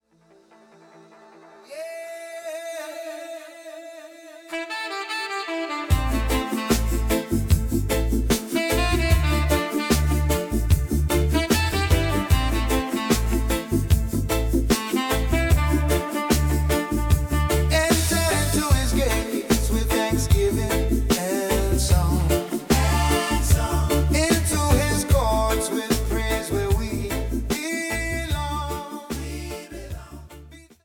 A joyful call to worship with celebratory island vibes
Artist/Performer: (singer/band) – AI